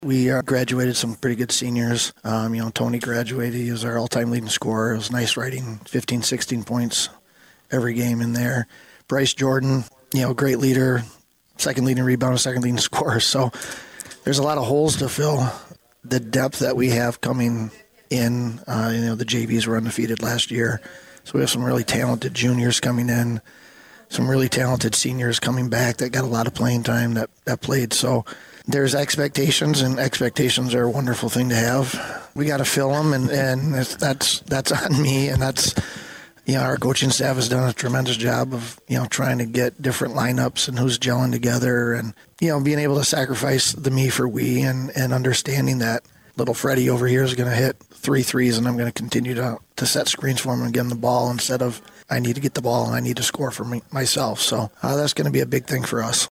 The girls basketball coaches preview show will be this Sunday from 10am-1pm at Skytech Sports Bar in Adrian on 96.5 The Cave and Lenawee TV.